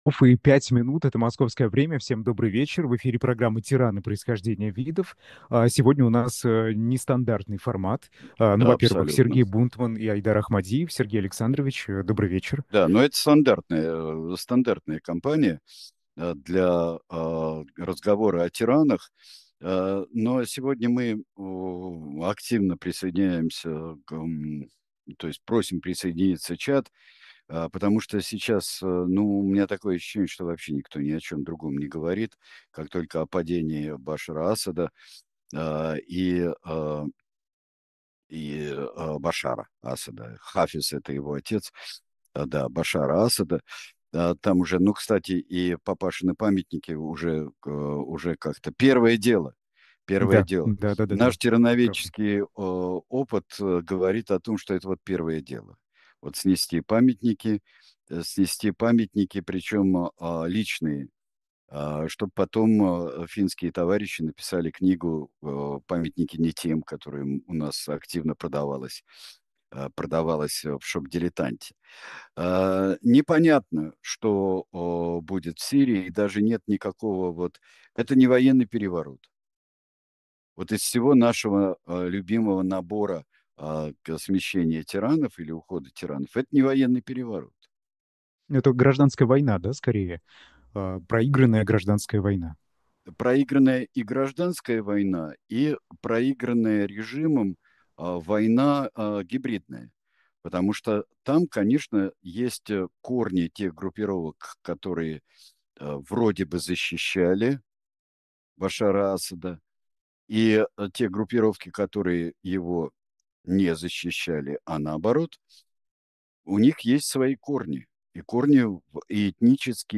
Разговор со зрителями